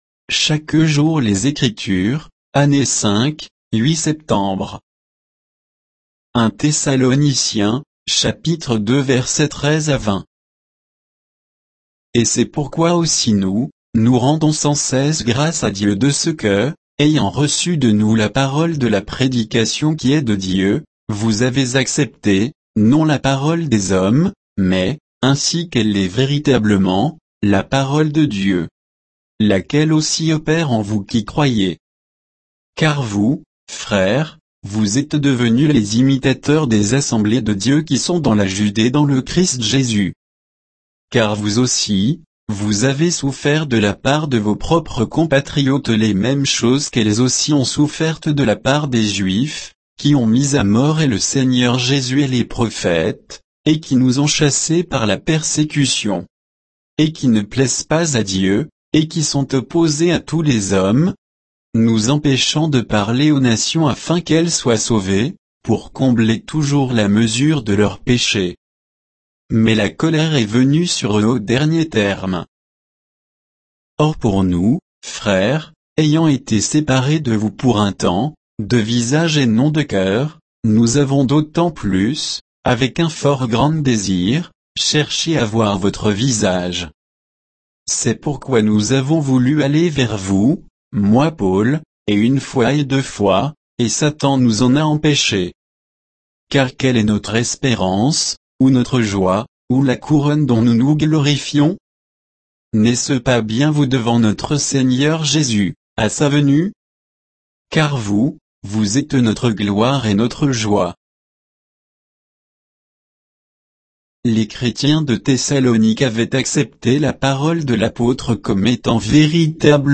Méditation quoditienne de Chaque jour les Écritures sur 1 Thessaloniciens 2